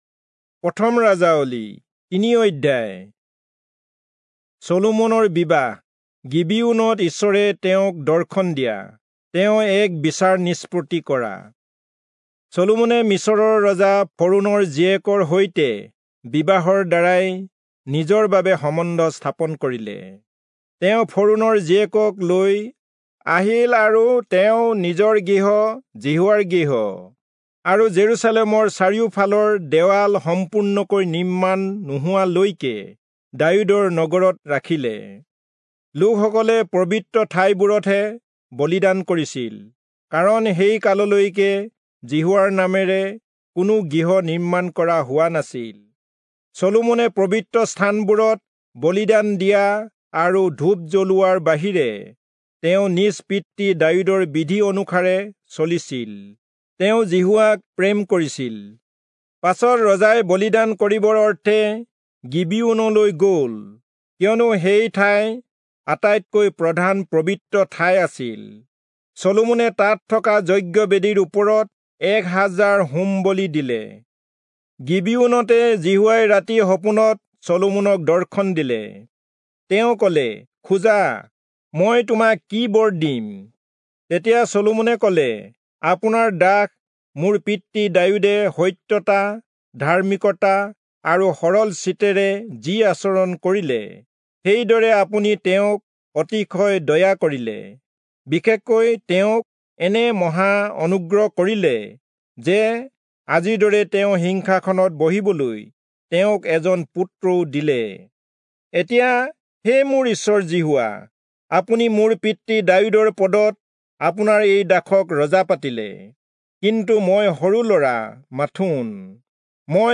Assamese Audio Bible - 1-Kings 13 in Irvte bible version